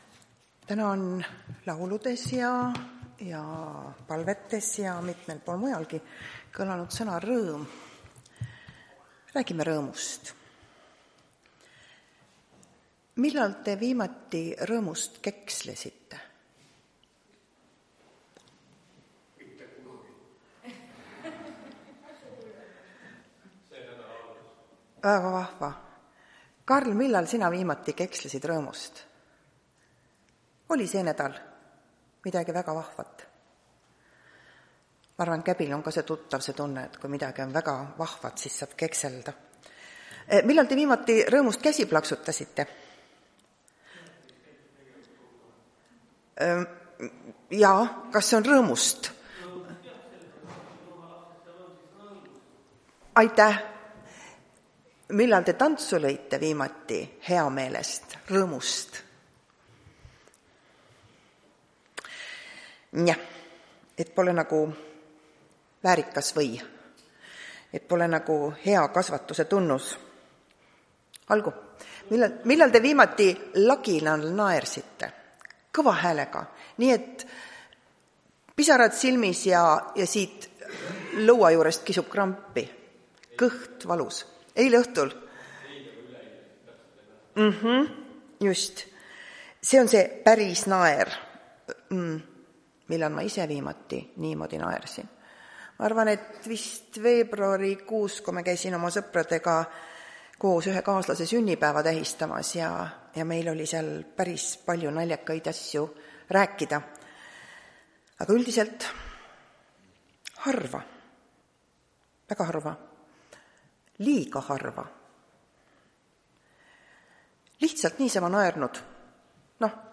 Tartu adventkoguduse 21.03.2026 teenistuse jutluse helisalvestis.